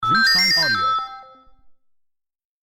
Nuovo carillon rapido 0001 di notifica del messaggio
• SFX